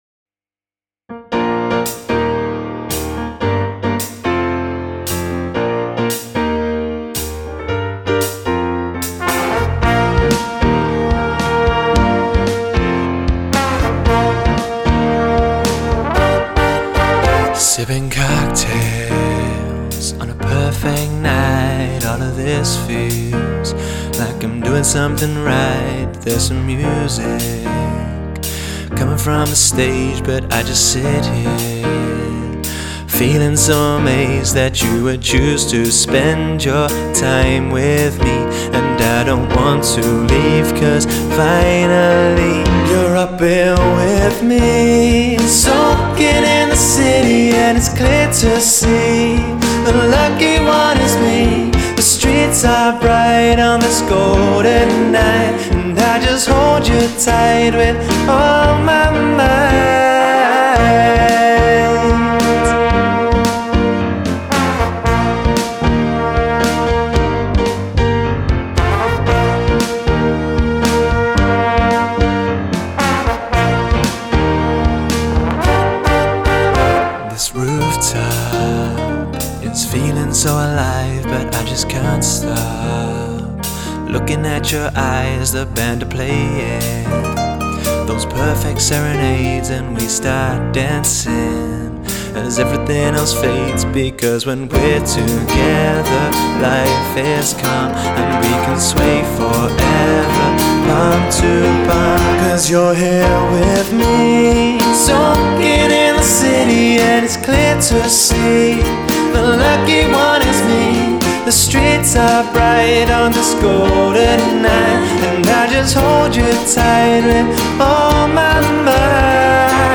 backing voc